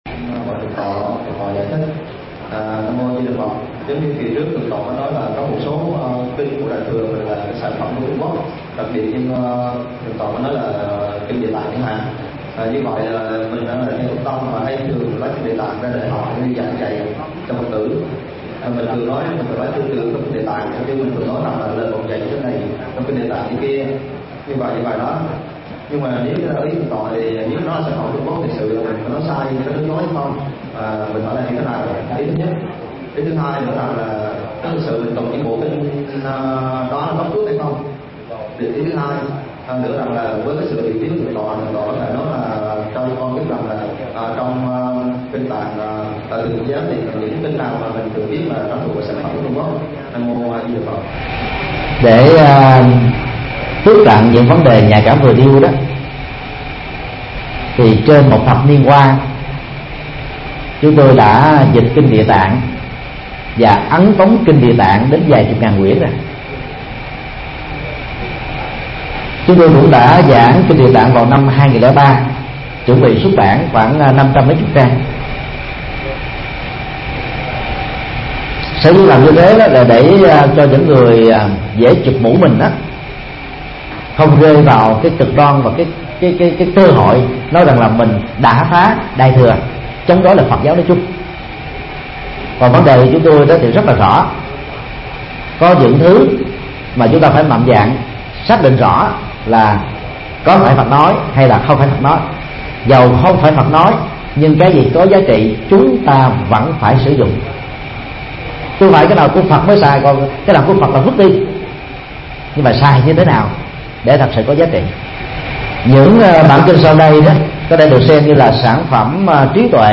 Vấn đáp: Kinh điển Phật giáo Trung Quốc